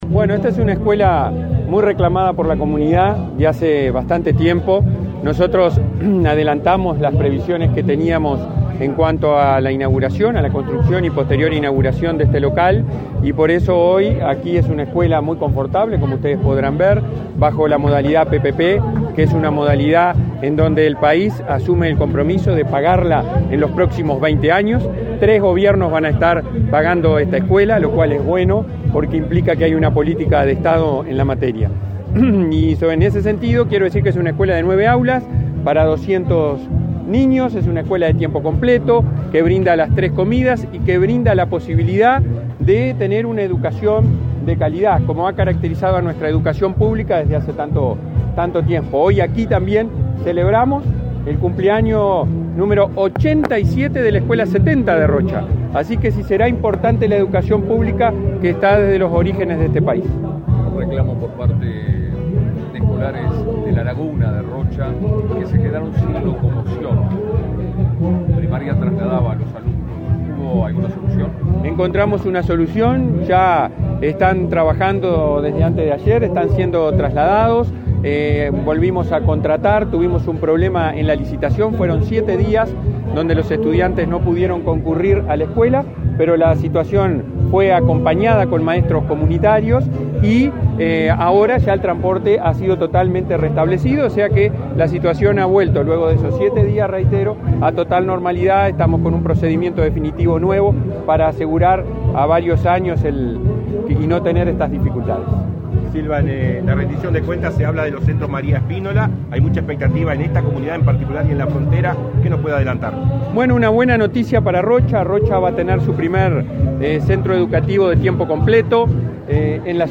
Declaraciones a prensa del presidente del Codicen de la ANEP, Robert Silva
Declaraciones a prensa del presidente del Codicen de la ANEP, Robert Silva 29/07/2022 Compartir Facebook X Copiar enlace WhatsApp LinkedIn El presidente del Consejo Directivo Central (Codicen) de la Administración Nacional de Educación Pública (ANEP), Robert Silva, participó, este 29 de julio, en la inauguración de la sede de la escuela n.° 96, de tiempo completo, en Punta del Diablo, departamento de Rocha.